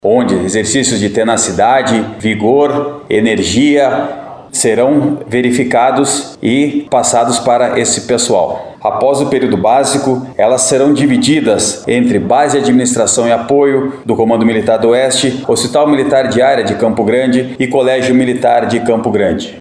Em entrevista à FM Educativa MS